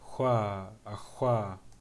labzd voiceless velar fricative
Labialized_voiceless_velar_fricative.ogg.mp3